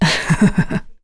Seria-Vox-Laugh.wav